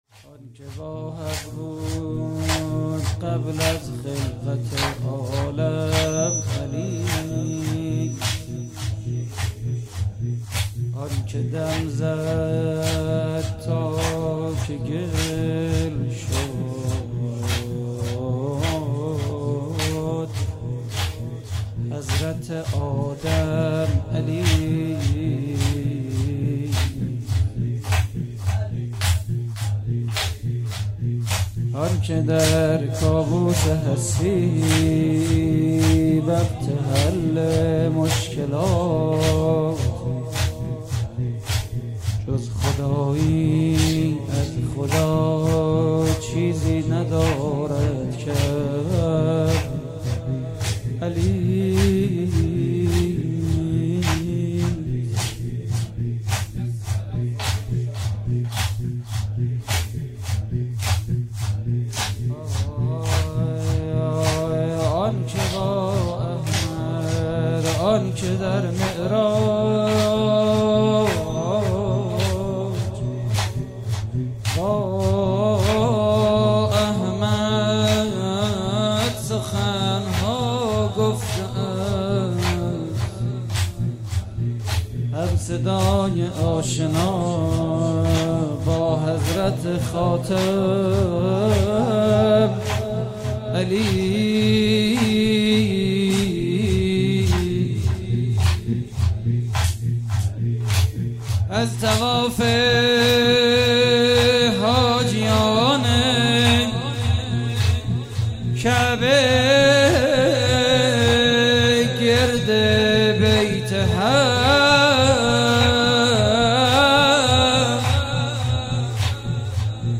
مراسم عزاداری شهادت حضرت امیر (ع) (22 رمضان)